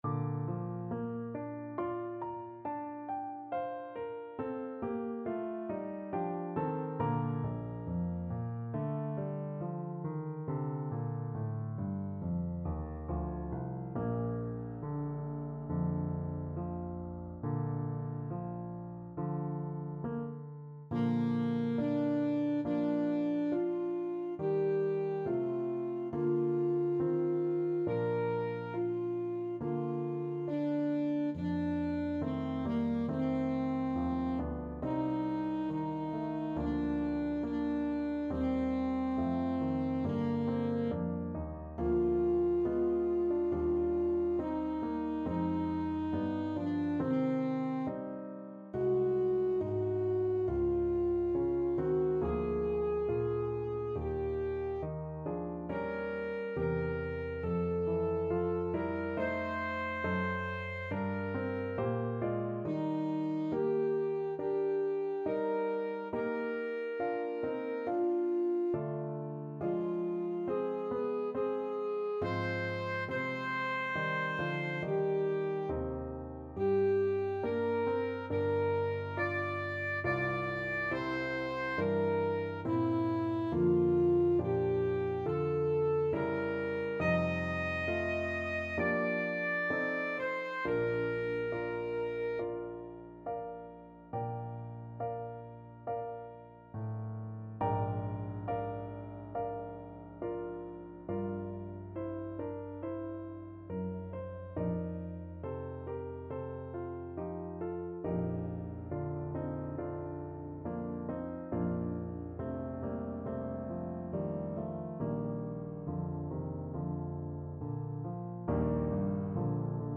Alto Saxophone version
Alto Saxophone
2/2 (View more 2/2 Music)
Slow =c.69
Classical (View more Classical Saxophone Music)